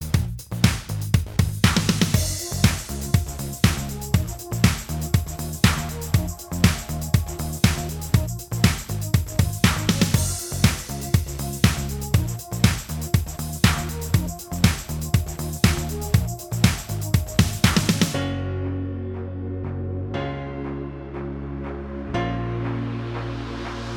Minus All Guitars Pop (1990s) 3:50 Buy £1.50